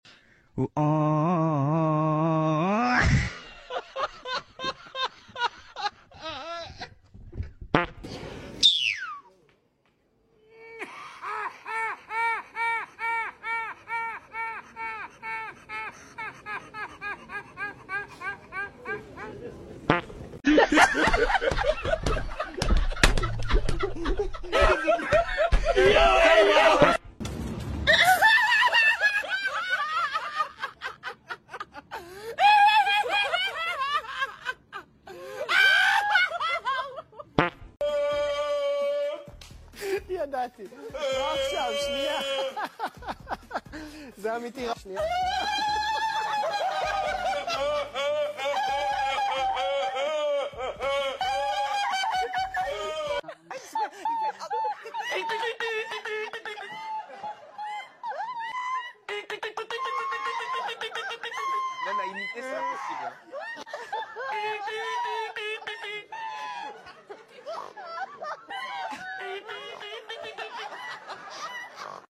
People Withe The Funniest Laugh